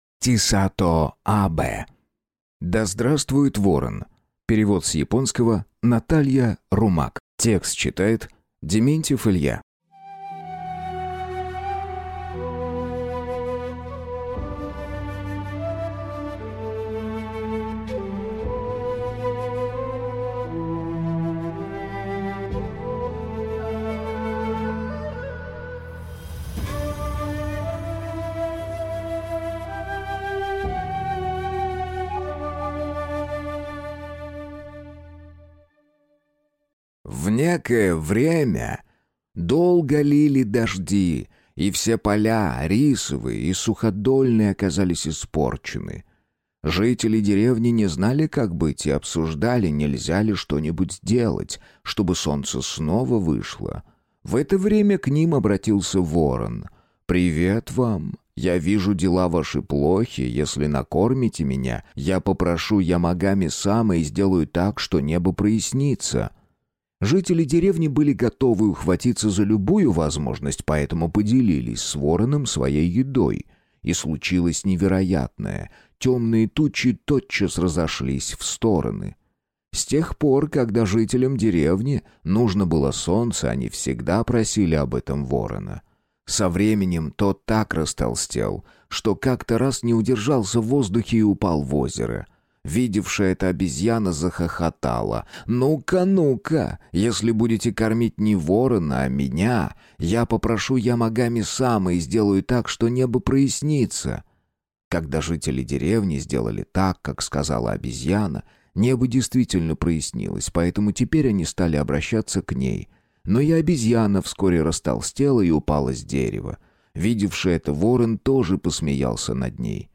Аудиокнига Да здравствует ворон!